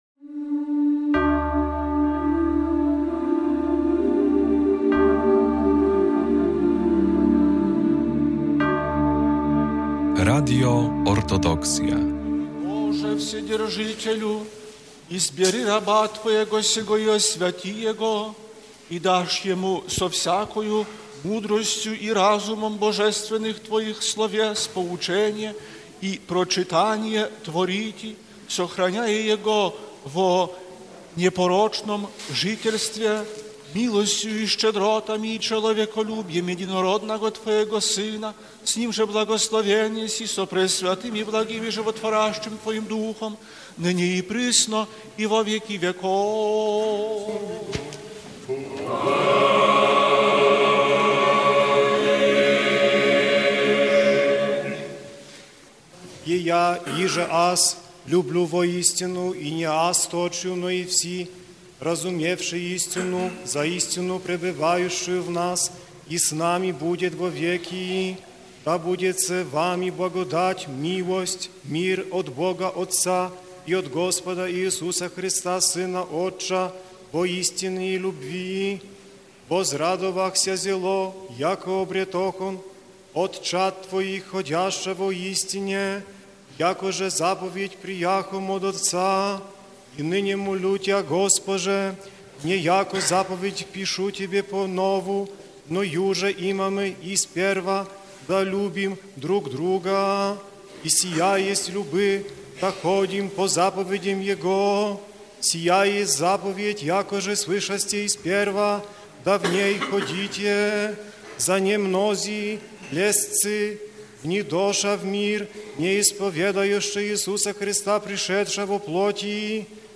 15 lutego w niedzielę o Strasznym Sądzie, która w tym roku wypadła w dniu święta Spotkania Pańskiego, JE Najprzewielebniejszy Jakub Arcybiskup Białostocki i Gdański, odprawił Boską Liturgię w białostockiej Katedrze św. Mikołaja. Tuz przed rozpoczęciem nabożeństwa Arcybiskup poświecił przyniesione przez wiernych świece.